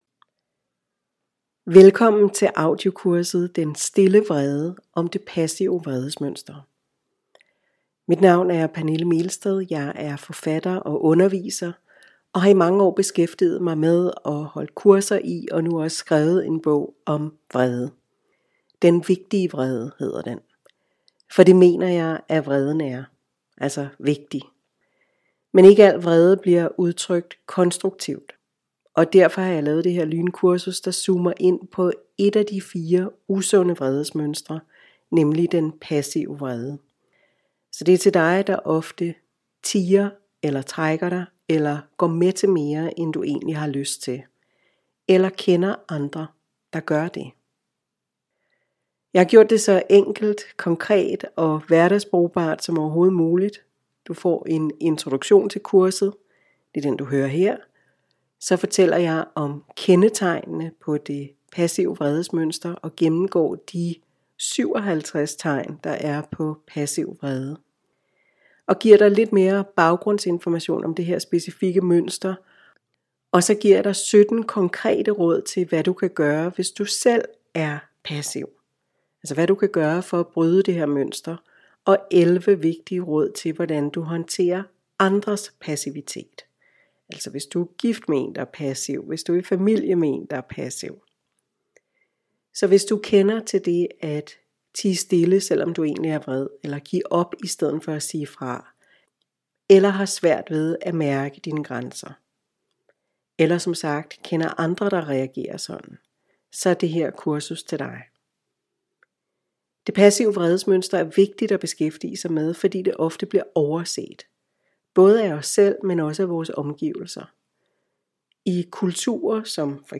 🎧  audiokursus